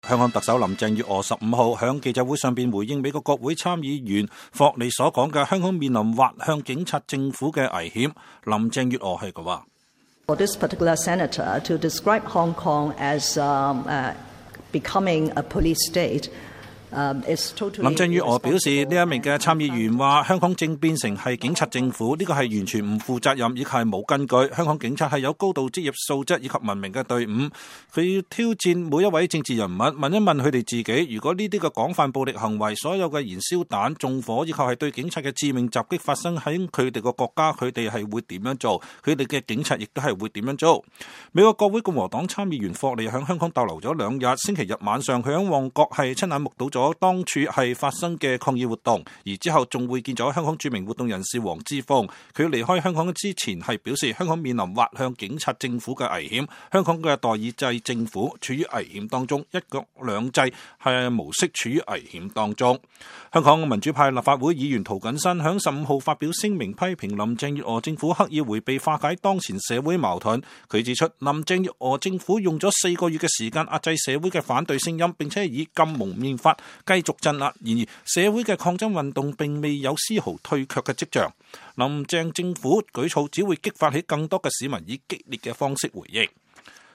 香港特首林鄭月娥15日在記者會上回應美國國會參議員霍利所說的“香港面臨滑向警察政府（ police state）的危險”。